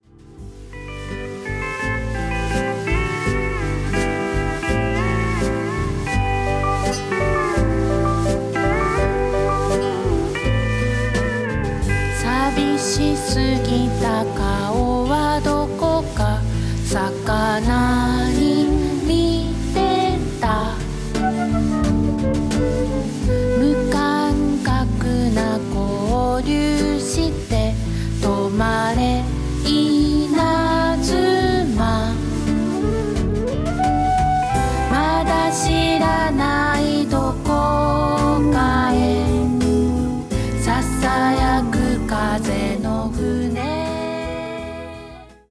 震える子猫を抱くような歌声。
スティール・ギターやサックス、リコーダーにヴィブラフォンも加わった７人体制のバンドサウンドはほんのりポップな装い。